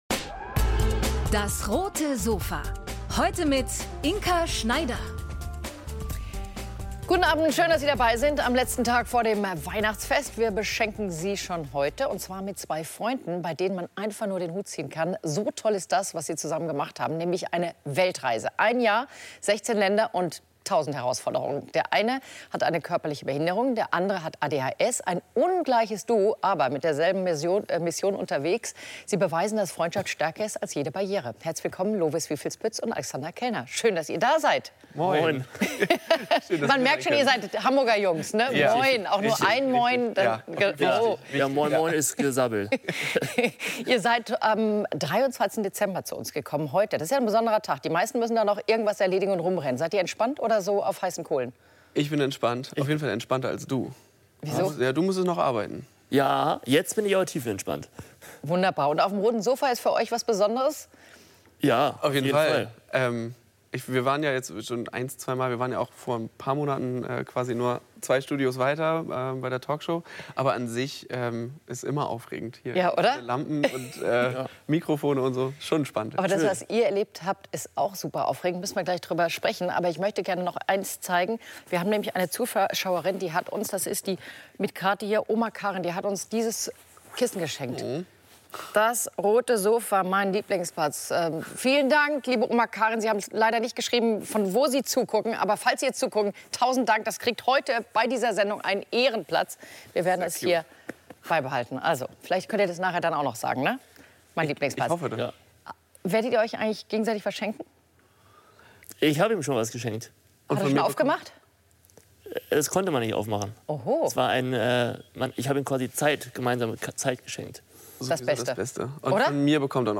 Auf dem Roten Sofa berichten die beiden über die Tücken ihrer Reise, ihre Freundschaft und ihre Überzeugung: Abenteuer sind für jeden da.